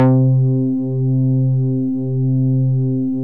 MOOG #8  C4.wav